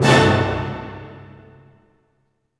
Index of /90_sSampleCDs/Roland LCDP08 Symphony Orchestra/HIT_Dynamic Orch/HIT_Orch Hit Dim
HIT ORCHDI00.wav